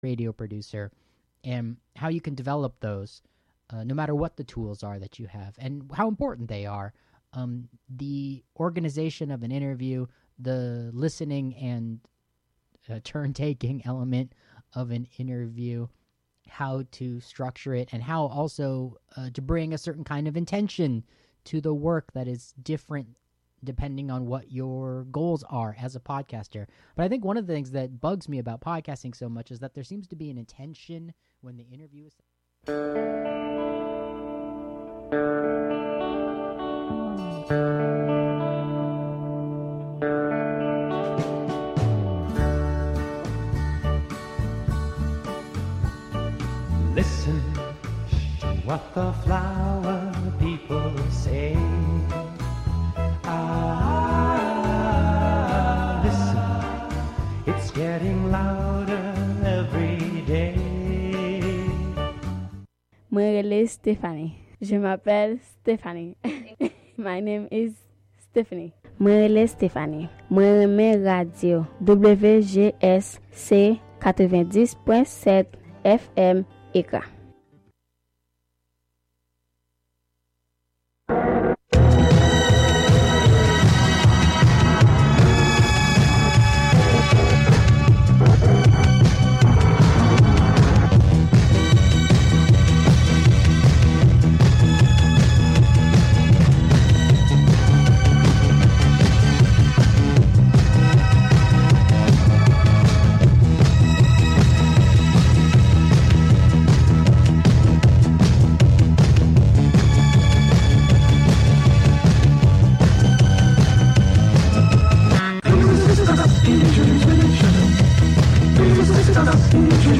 Tune in for the latest episode of the serial "I Have Seen Niagara": S3 EP5 - Psychic Network Niagara, part 5; The Missing and Probably Dead Podcast.
And what's with the sound of a jam band playing under everyone's breath as they sleep?